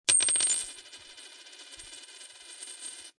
Index of /html/coin_sounds/